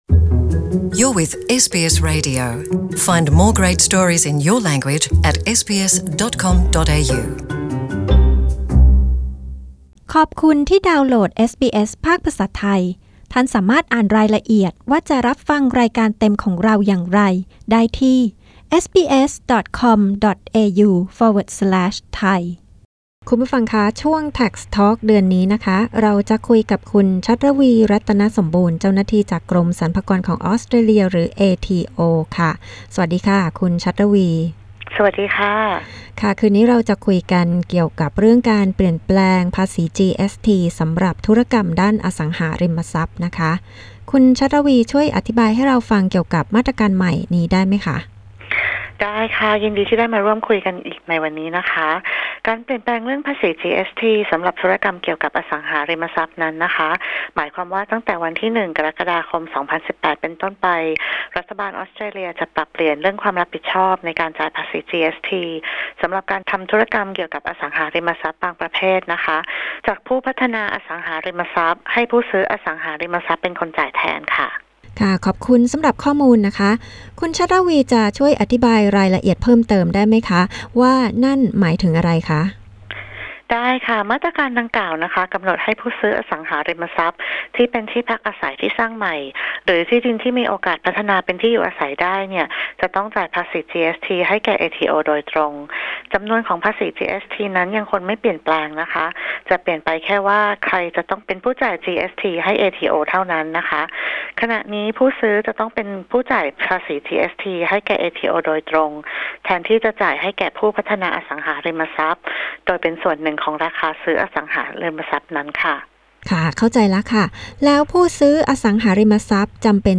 เจ้าหน้าที่จากกรมสรรพากรของออสเตรเลีย (เอทีโอ) อธิบายการที่ผู้ซื้อจะต้องจ่ายภาษีจีเอสทีสำหรับการทำธุรกรรมอสังหาริมทรัพย์